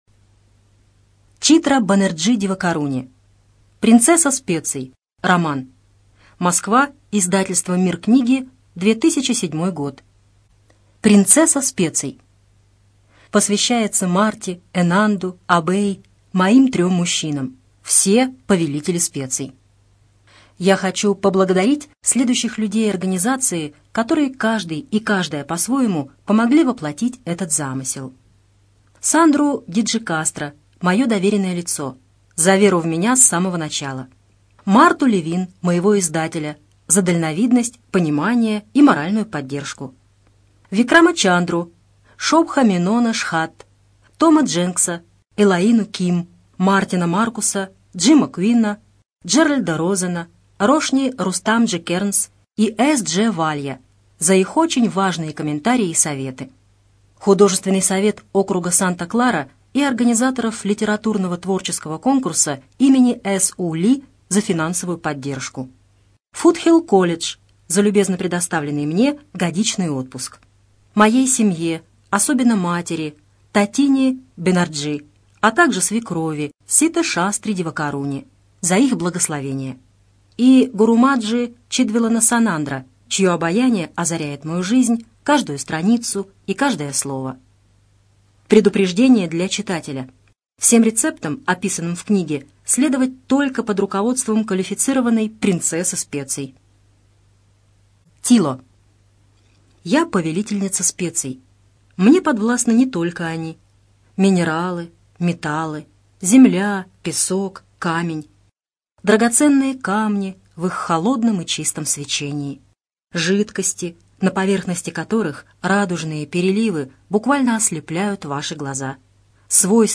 ЖанрСовременная проза
Студия звукозаписиКемеровская областная специальная библиотека для незрячих и слабовидящих